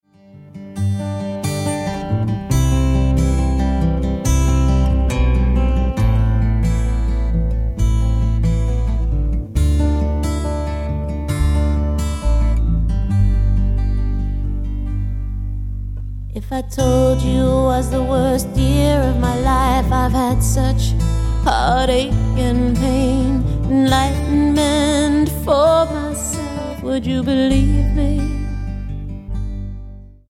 Alternative,Blues,Folk